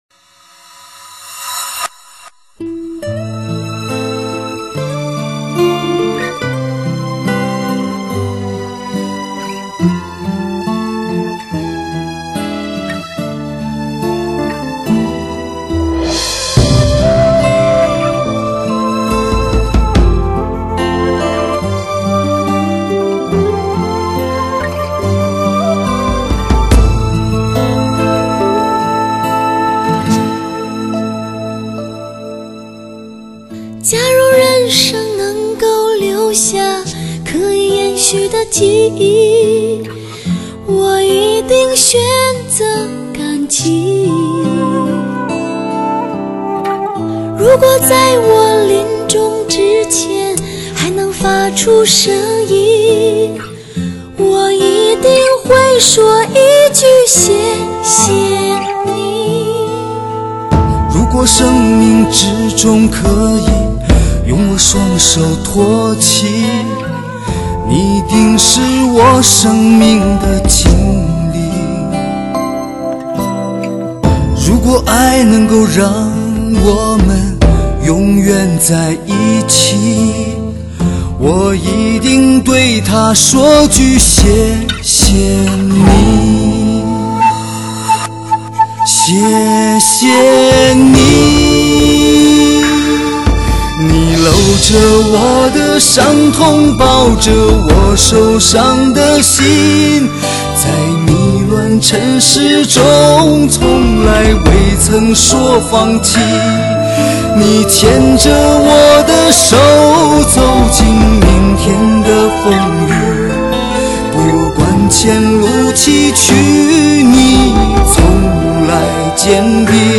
细腻的DTS情歌，永恒典范，空前强势，爱情不留白，经典对唱震撼登场，听最动听的情歌说最动听的情话爱最爱的人。
强势DTS编码还原多维真声，真正6.1DTS-ES CD环绕声体验，带来超乎想象的震撼级逼真实感。